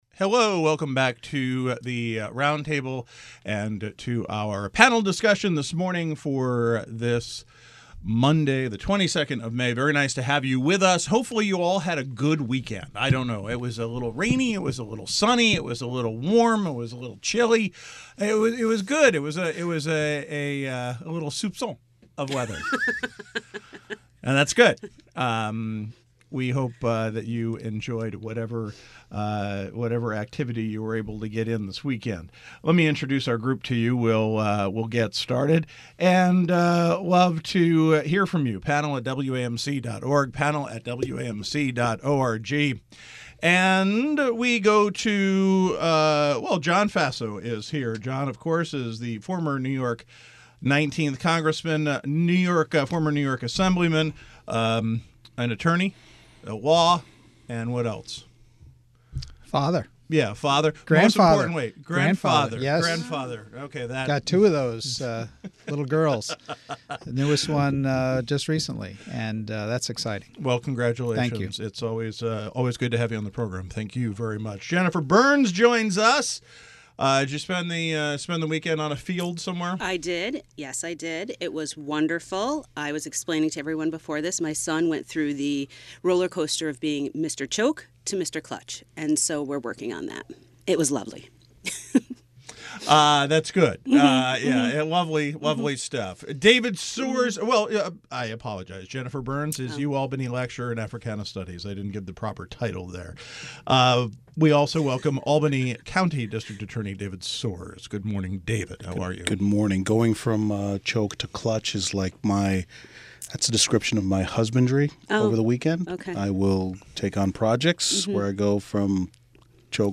The Roundtable Panel: a daily open discussion of issues in the news and beyond.